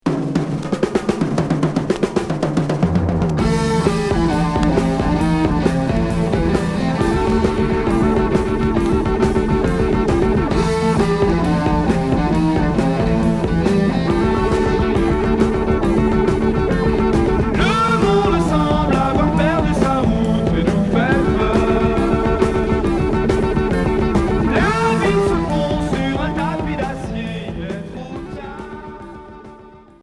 Heavy rock pop